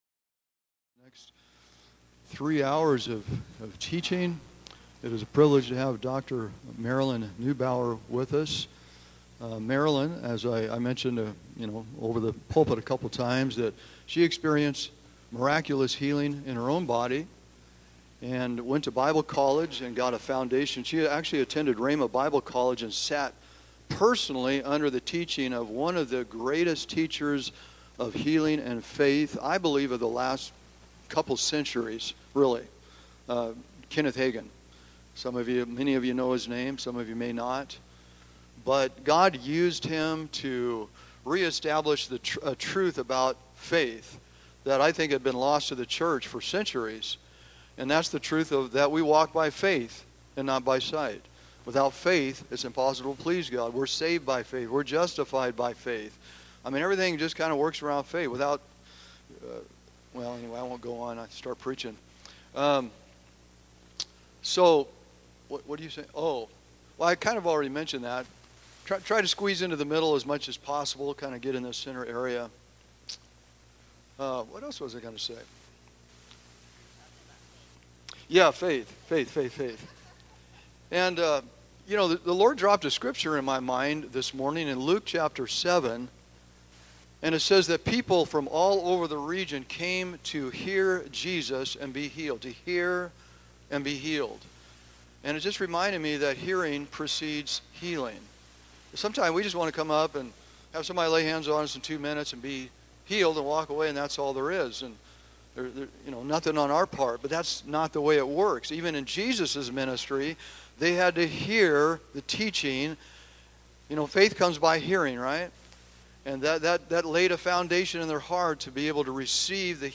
Healing Seminar Session 1